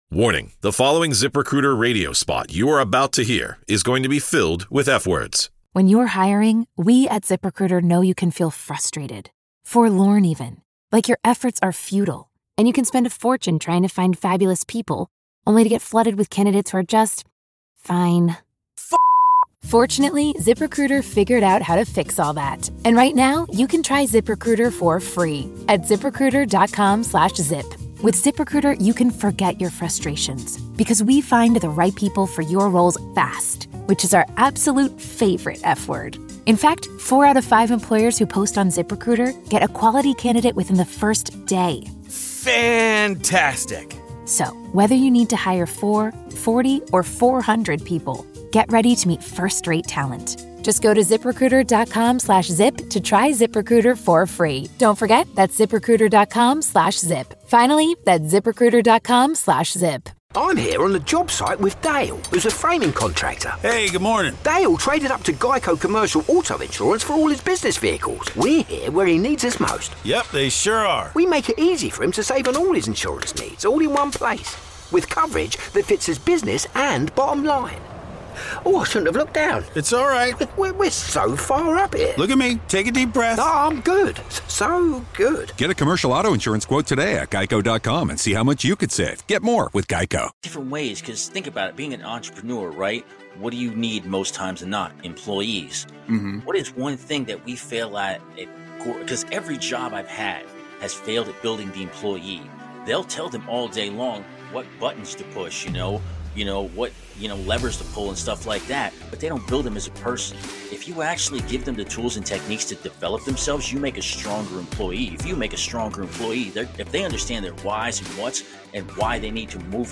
Through candid conversations with leaders and changemakers, you'll discover how to grow your venture while protecting your health, relationships, and purpose.